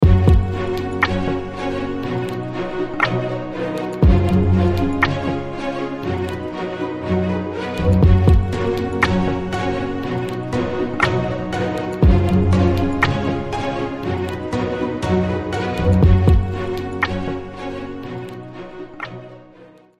Instrumental Ringtones